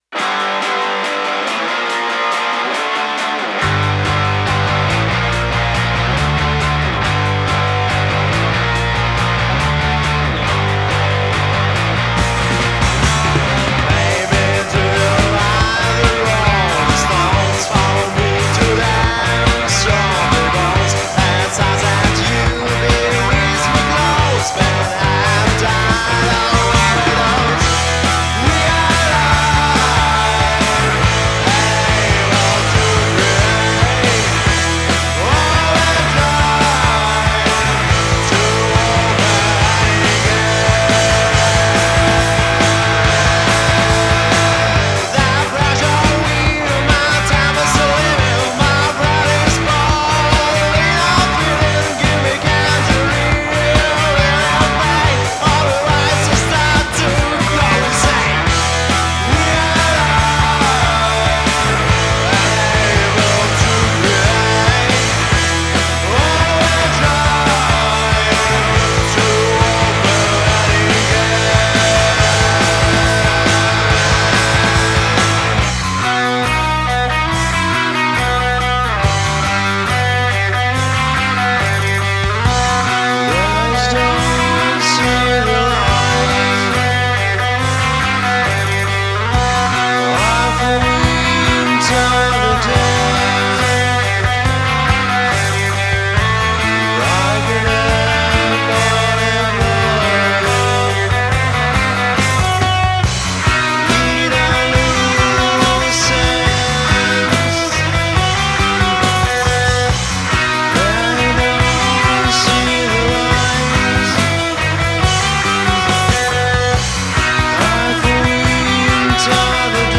Психоделический рок, Гараж, лоу-фай.
Столкнулся с проблемой в подорожании звуку 60-70.
От низа в песне ребята отказались для аутентичности.